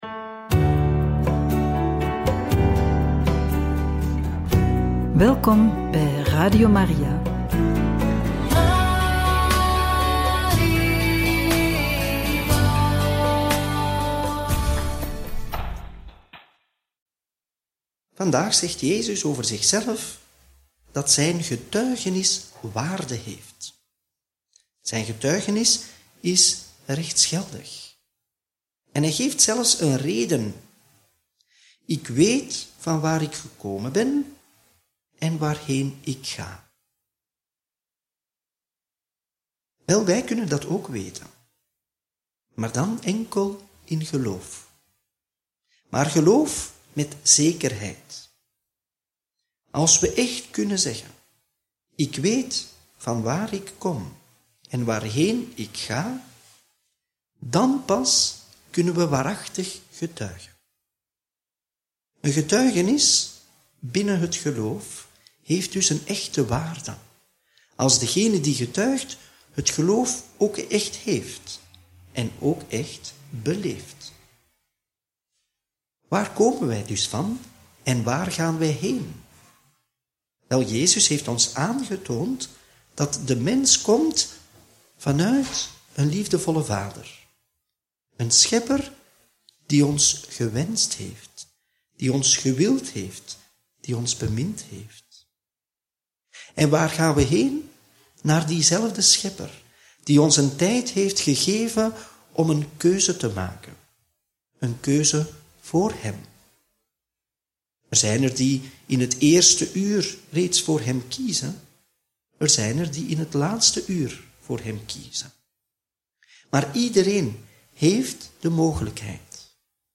Homilie bij het Evangelie van maandag 7 april 2025 – Joh. 8,12-20